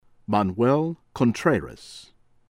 CHILEAN CHIH-lee-ahn